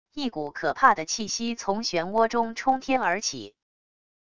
一股可怕的气息从漩涡中冲天而起wav音频生成系统WAV Audio Player